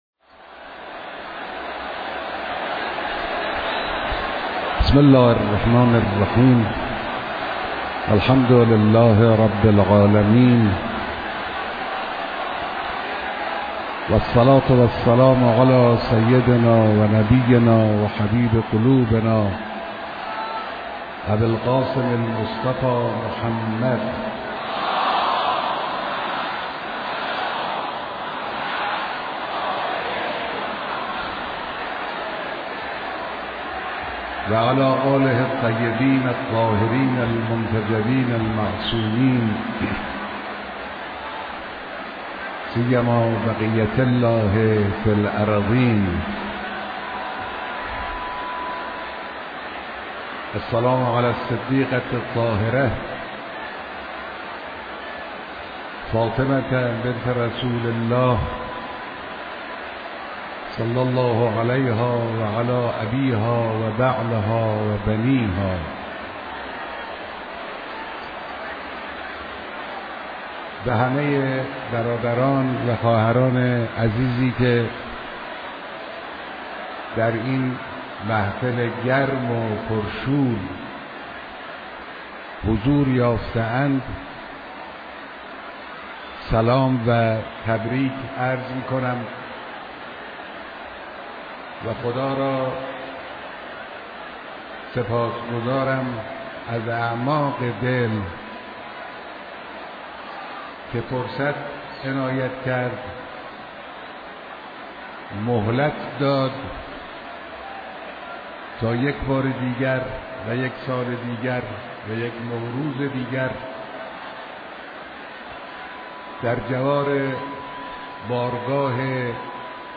مشروح سخنان امیر آیت الله خامنه ای.mp3